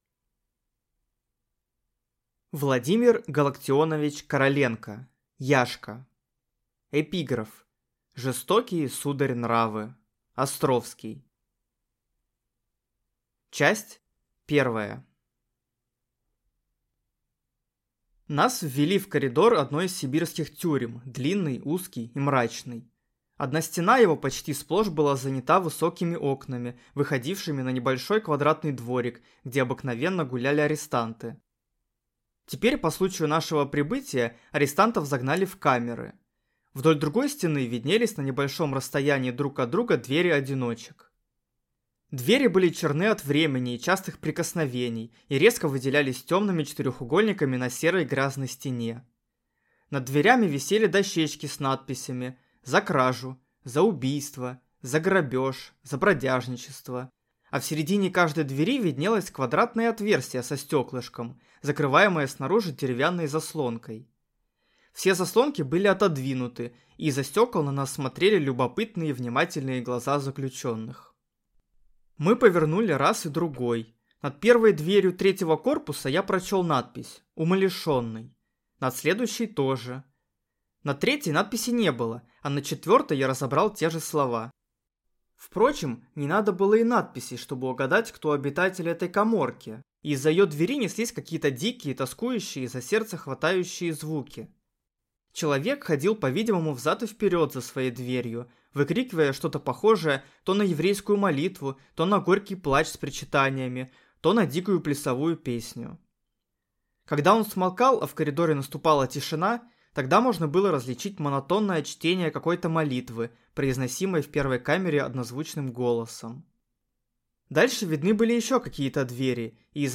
Аудиокнига Яшка | Библиотека аудиокниг